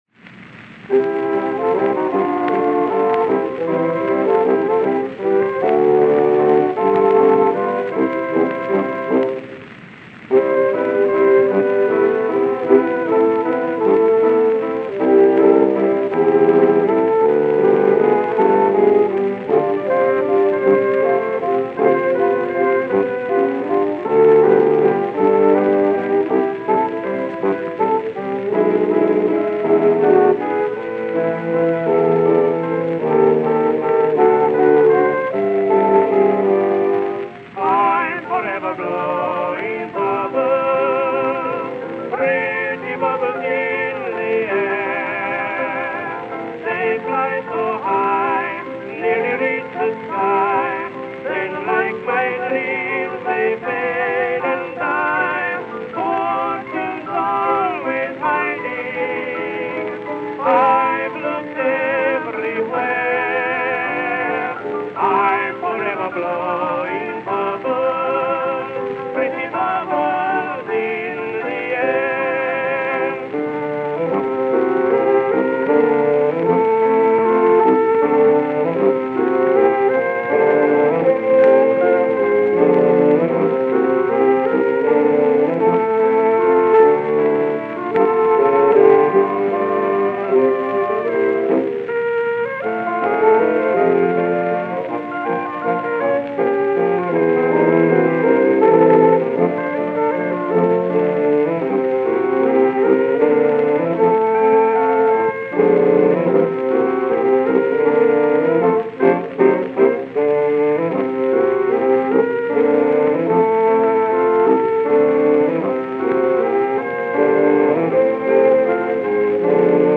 Voicing: Saxophone Sextet